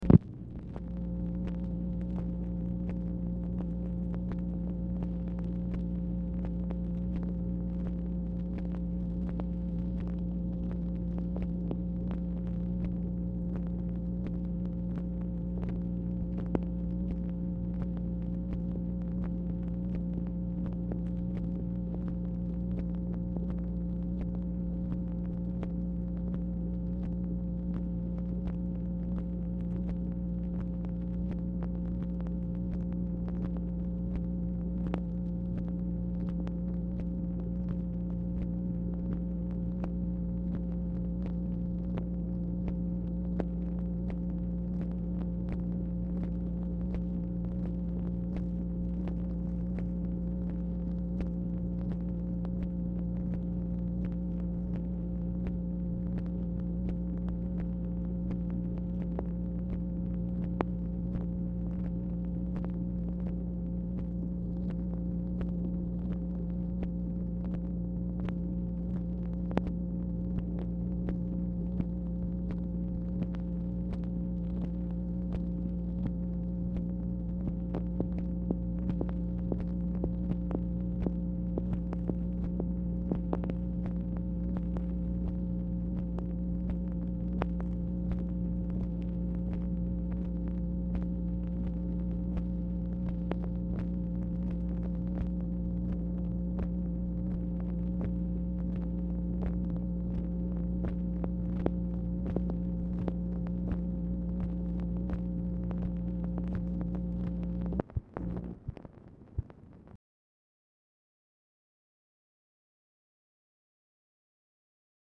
Telephone conversation # 10627, sound recording, MACHINE NOISE, 8/18/1966, time unknown | Discover LBJ